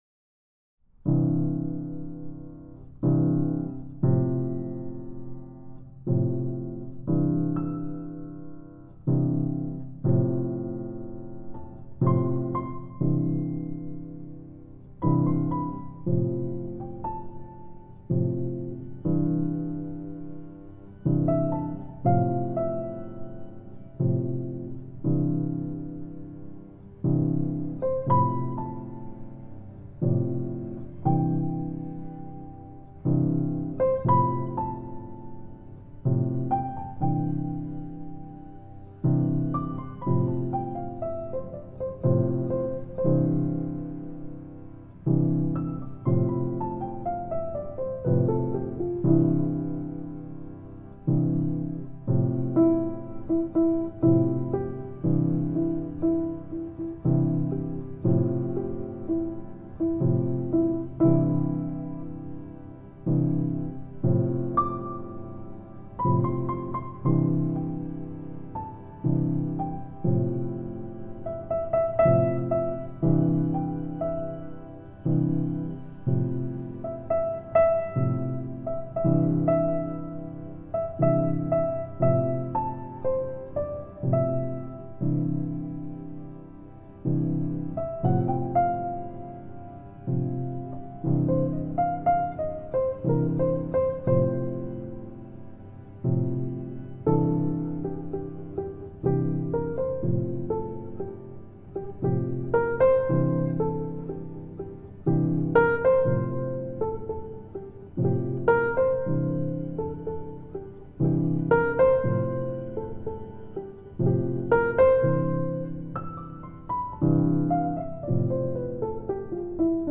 I’m using a felt over the strings of the piano to give it a softer sound. Also, this is my debut playing cello. 4 tracks of G and C drone, about all I’m capable of in the strings department.
piano-day-7.mp3